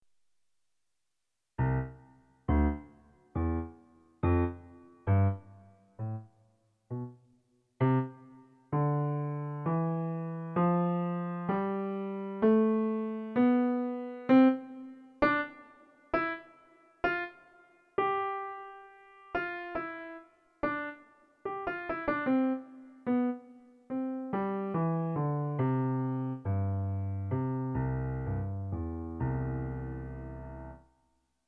doremi-piano-in.wav